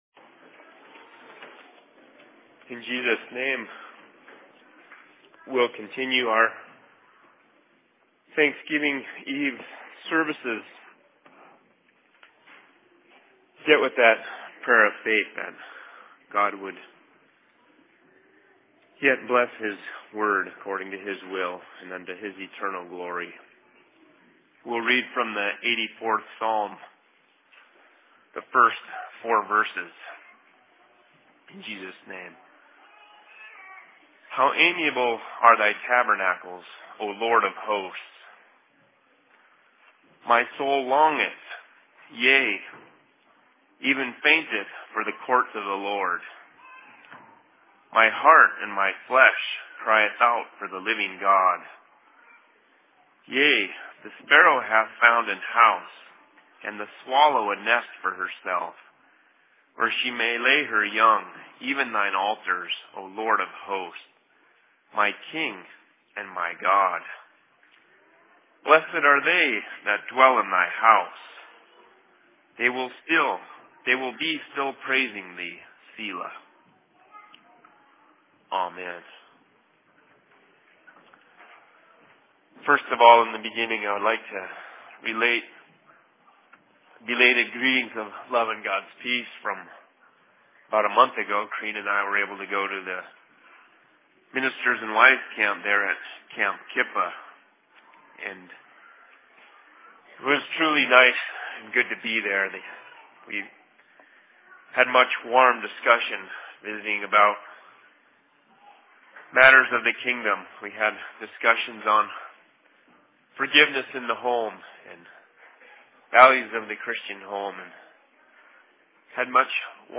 Thanks Giving Eve Services/Sermon in Seattle 21.11.2012
Location: LLC Seattle